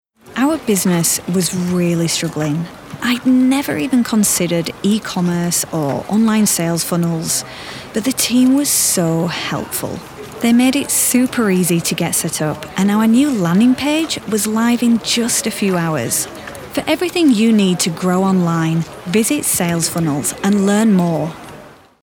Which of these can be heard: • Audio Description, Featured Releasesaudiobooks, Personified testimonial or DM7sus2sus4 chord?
Personified testimonial